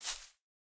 Minecraft Version Minecraft Version latest Latest Release | Latest Snapshot latest / assets / minecraft / sounds / block / azalea / step5.ogg Compare With Compare With Latest Release | Latest Snapshot
step5.ogg